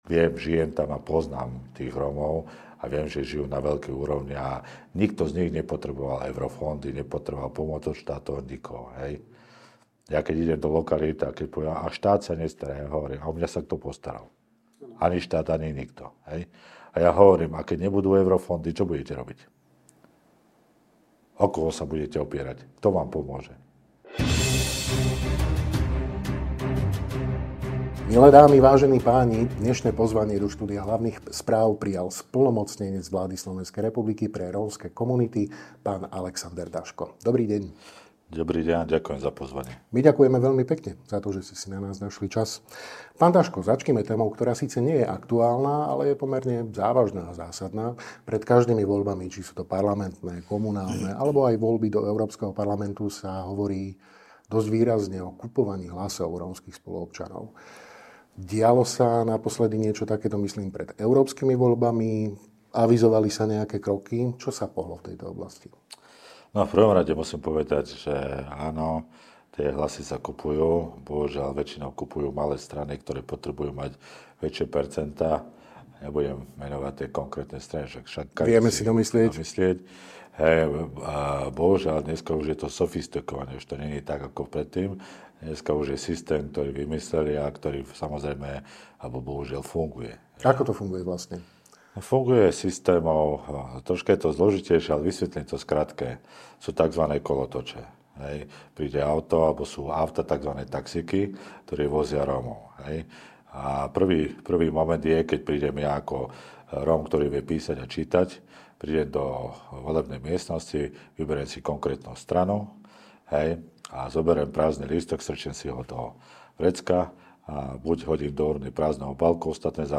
Splnomocnenec vlády pre rómske komunity v rozhovore pre Hlavné správy otvorene priznáva, že problém kupovania hlasov v osadách skutočne existuje a opisuje aj jeho fungovanie.